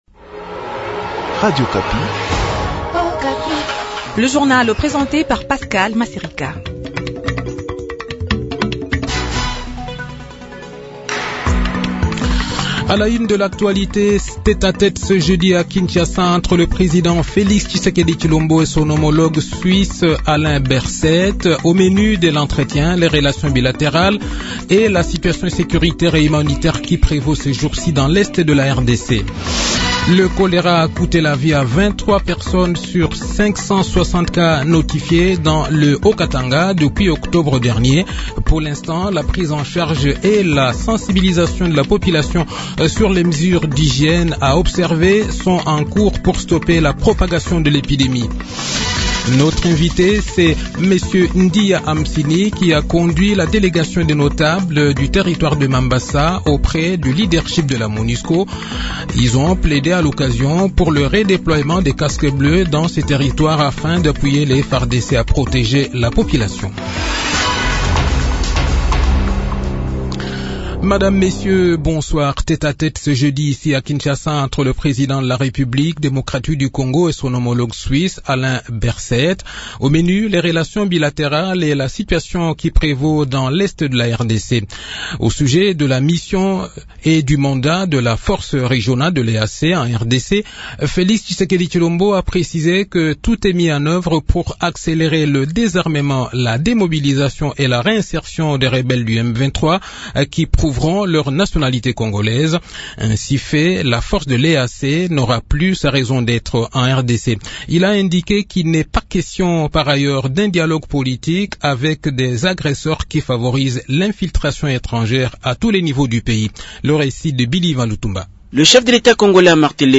Journal Soir
Le journal de 18 h, 13 Avril 2023